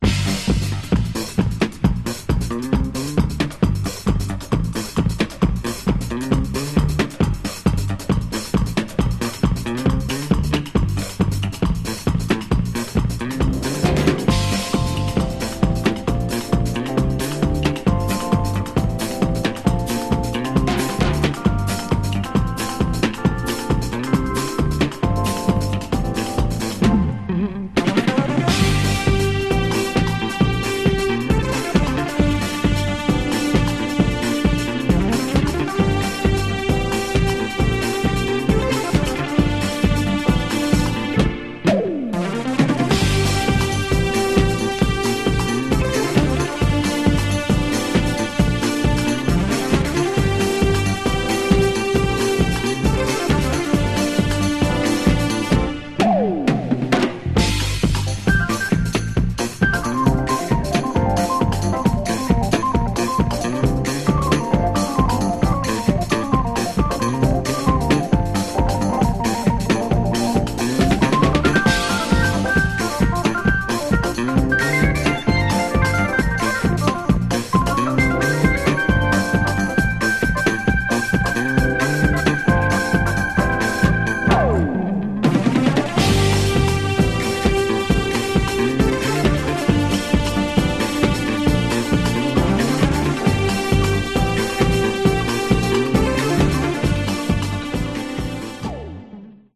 Genre: Funk/Hip-Hop/Go-Go